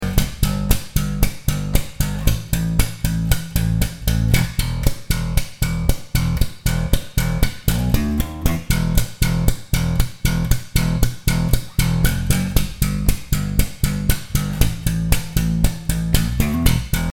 Using slapping techniques made popular by Larry Graham, Louis Johnson, Victor Wooten, Marcus Miller among others